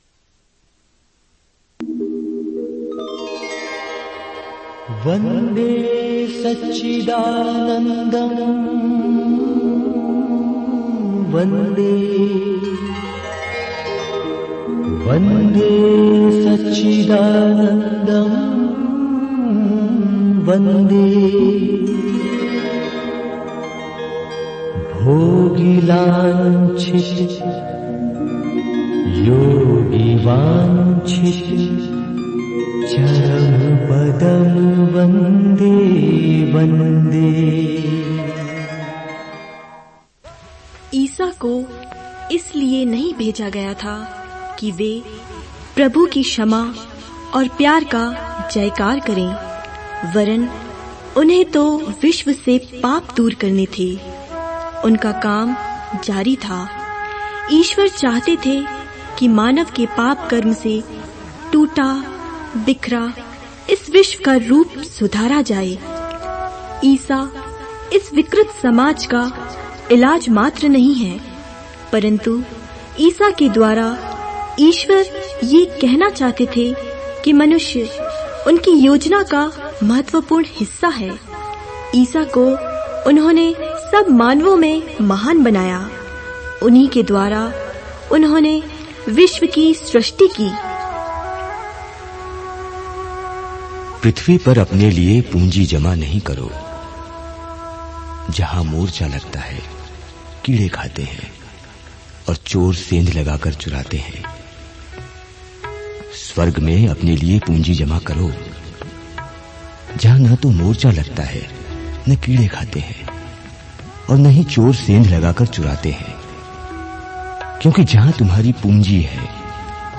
Directory Listing of mp3files/Hindi/Bible Dramas/Dramas/ (Hindi Archive)